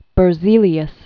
(bər-zēlē-əs, bĕr-sālē-s), Baron Jöns Jakob 1779-1848.